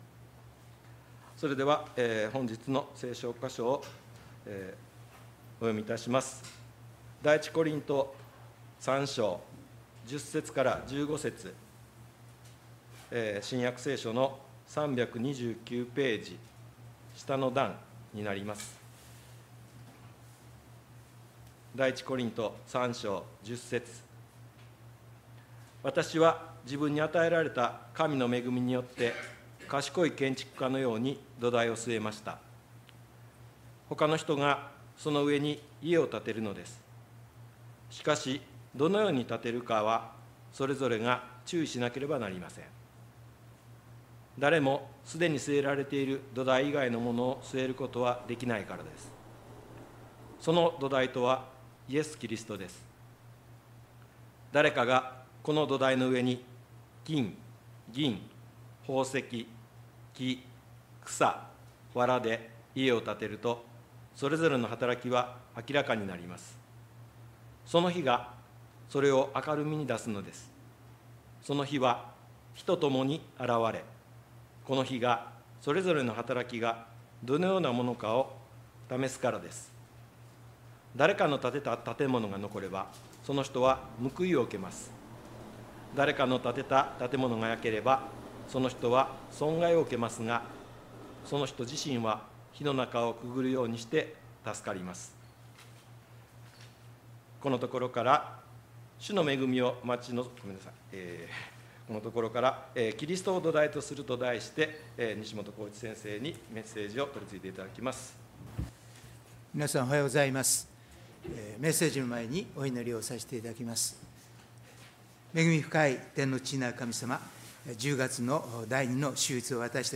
礼拝メッセージ「キリストを土台とする」│日本イエス・キリスト教団 柏 原 教 会